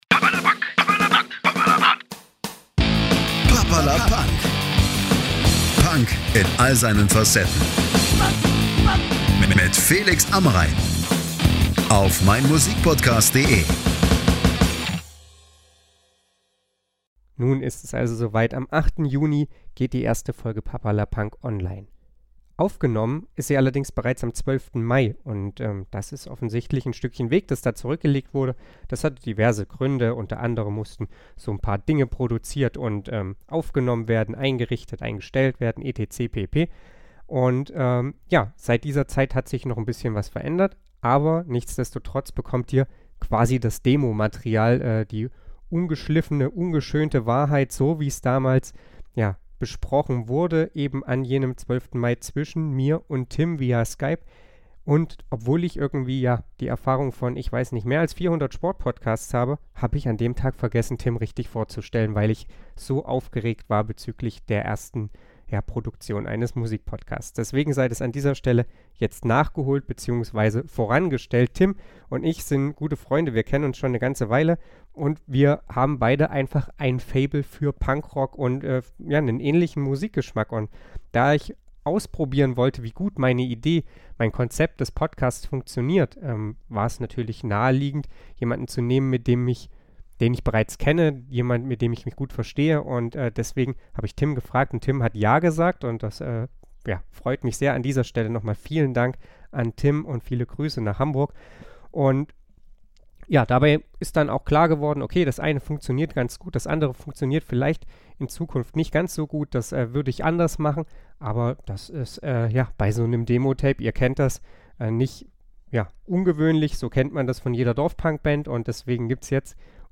Was ihr gleich hört, ist sowas wie das Demotape des Podcasts, gewissermaßen die Generalprobe, die in bester Punkmanier trotzdem den Weg in die Öffentlichkeit findet.